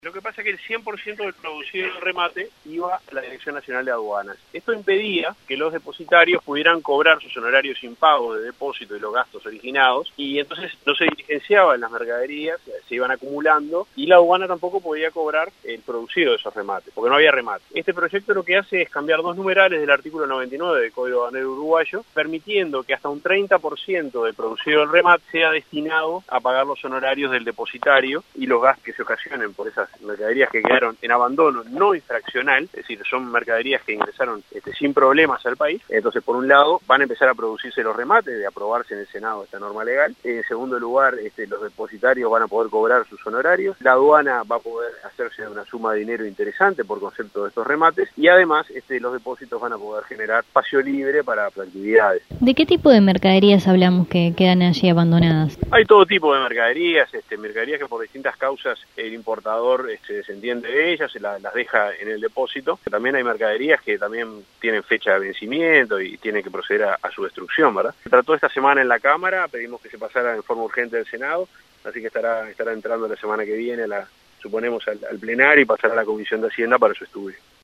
El diputado Juan José Olaizaola, quien presentó el proyecto, dijo a Informativo Universal que permite que hasta un 30% de lo producido en el remate sea destinado a pagar los honorarios del depositario y los gastos que se ocasionen por esas mercaderías en abandono pero que ingresaron al país sin problemas.